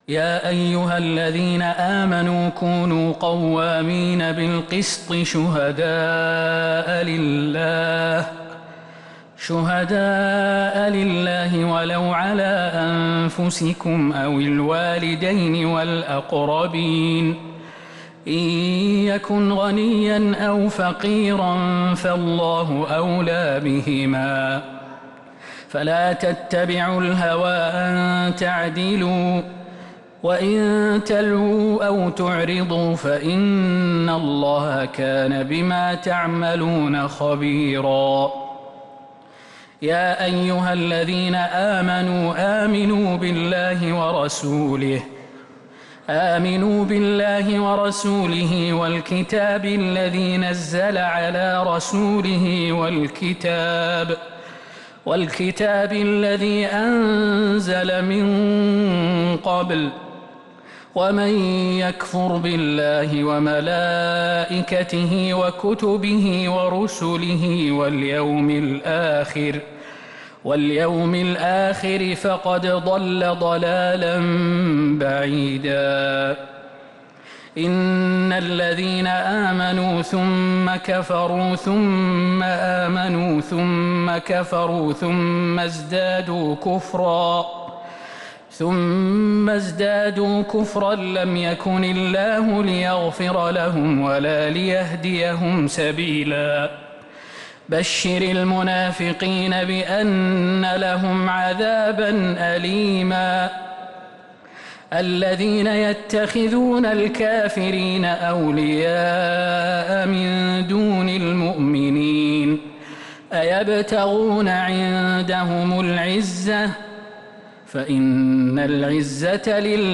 تراويح ليلة 7 رمضان 1447هـ من سورة النساء {135-176} Taraweeh 7th night Ramadan 1447H Surah An-Nisaa > تراويح الحرم النبوي عام 1447 🕌 > التراويح - تلاوات الحرمين